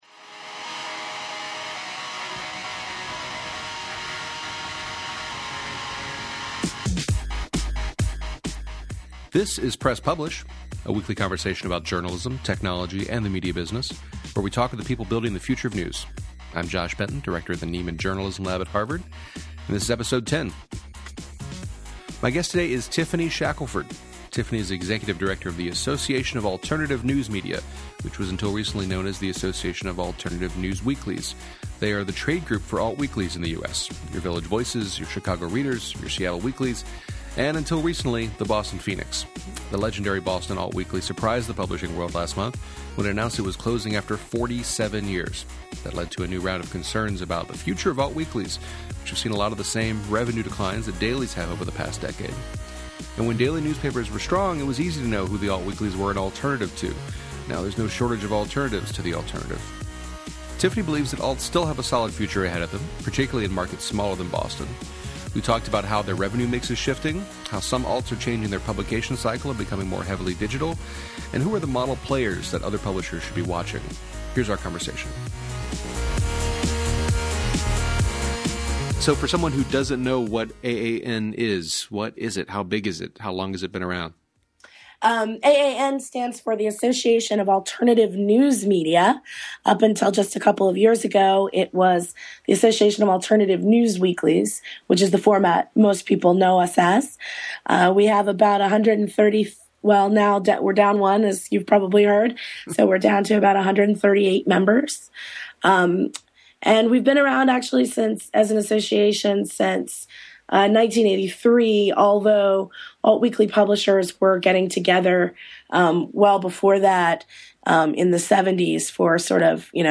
We talked about how their revenue mix is shifting, how some alts are changing their publication cycle and becoming more heavily digital, and who are the model players that other publishers should be watching.